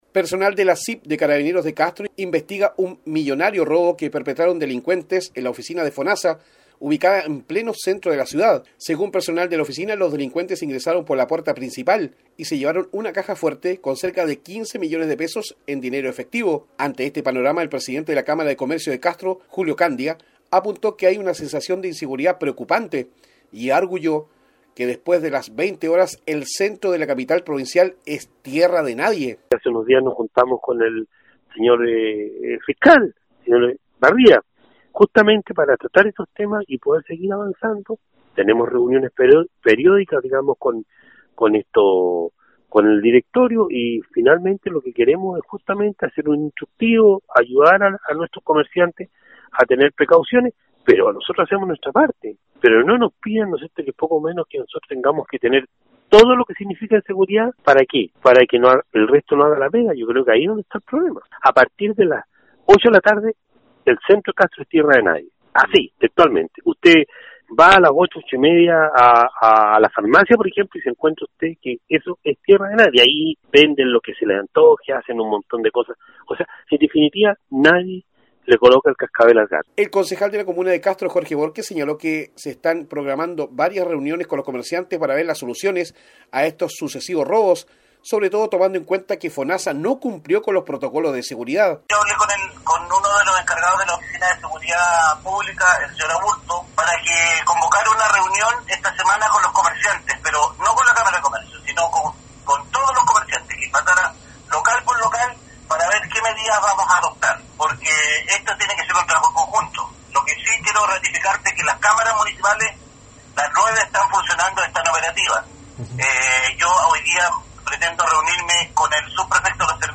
informe del corresponsal